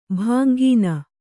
♪ bhāngīna